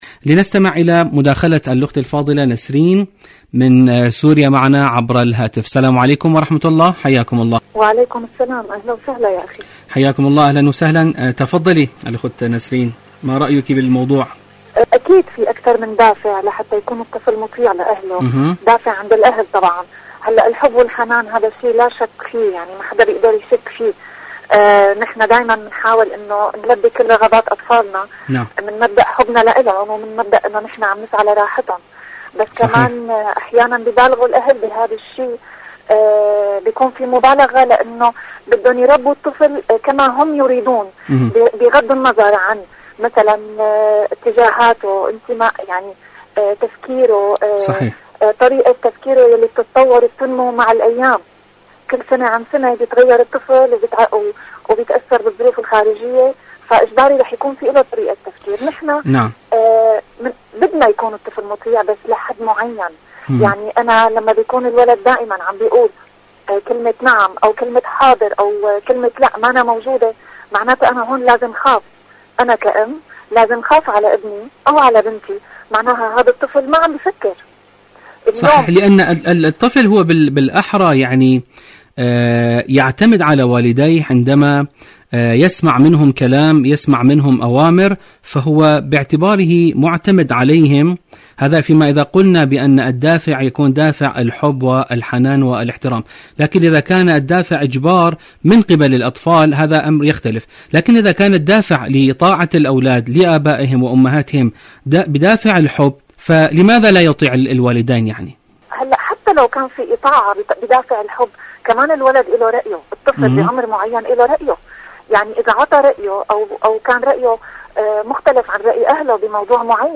مشاركة واتساب صوتية